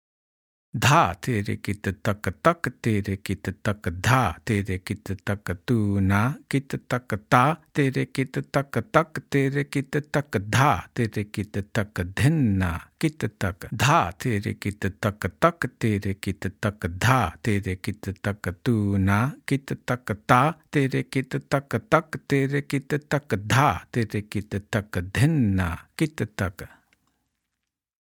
1x Speed – Spoken